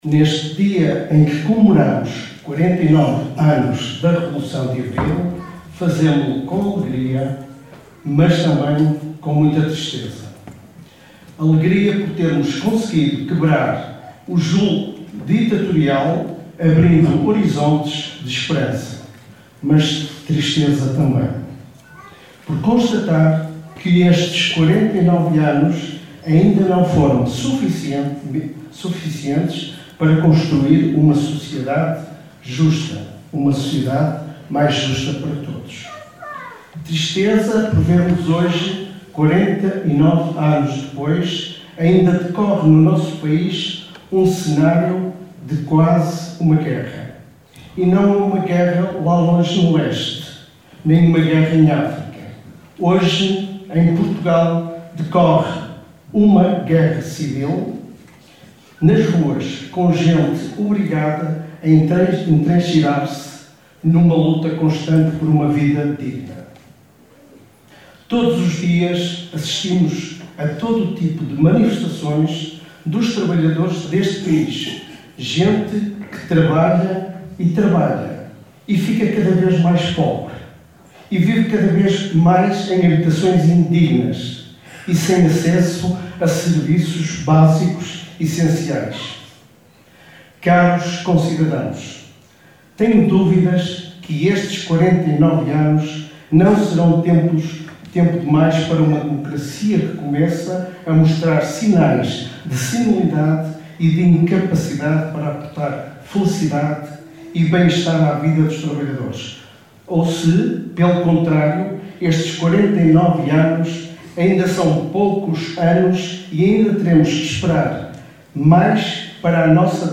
Para assinalar o 49º aniversário da revolução de abril, a Assembleia Municipal reuniu ontem em sessão solene no Teatro Valadares em Caminha.